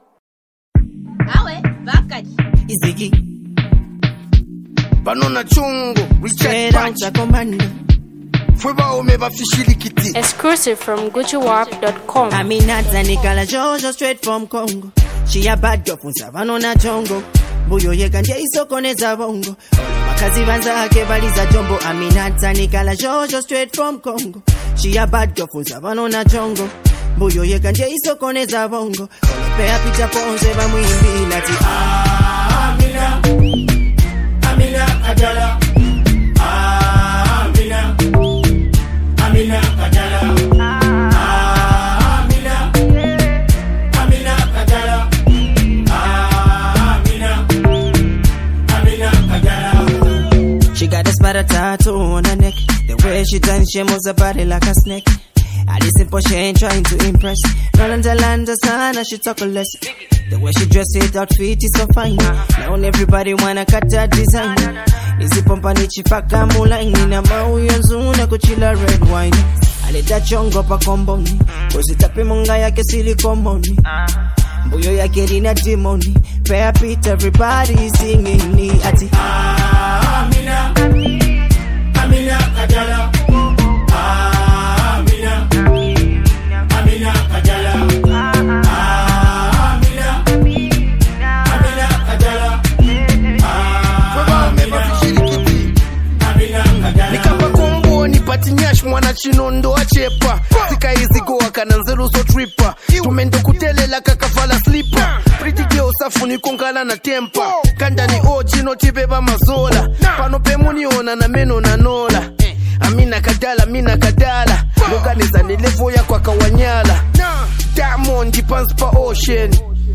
” is a ghetto street anthem that everyone’s talking about.